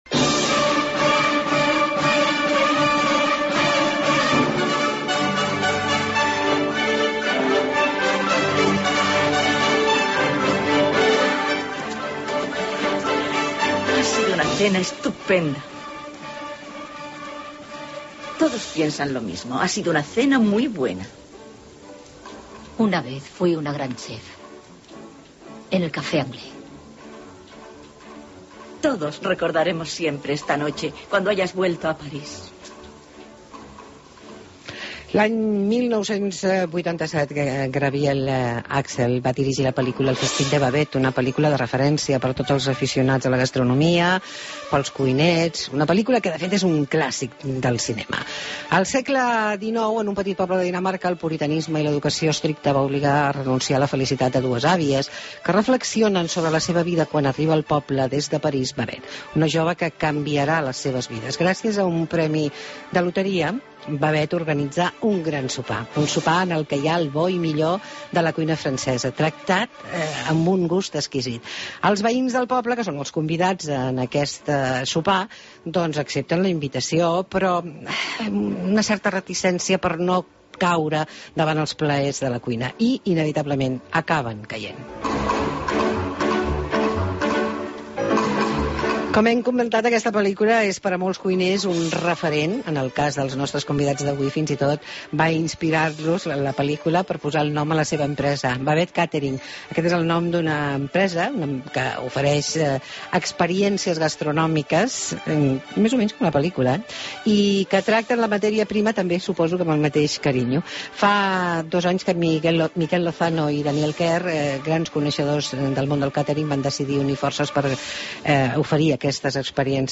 Entrevista amb els components de Babette Catering, actuals campions del món